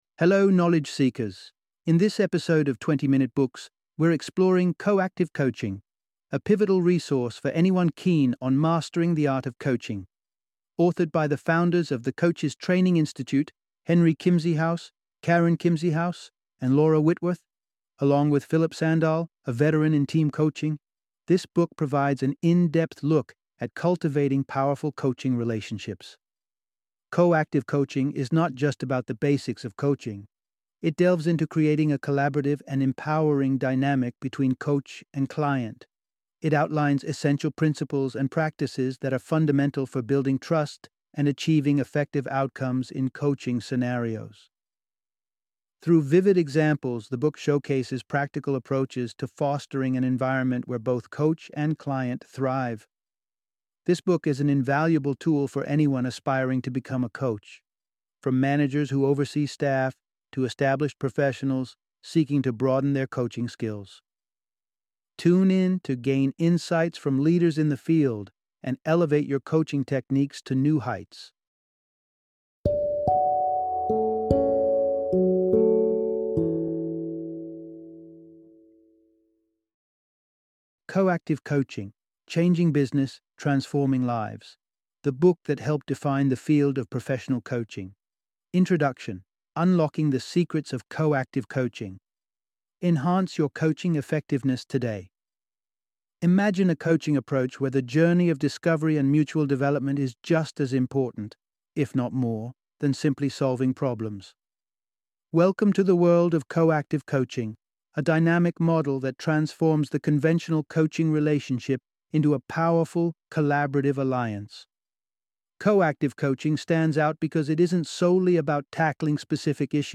Co-Active Coaching - Audiobook Summary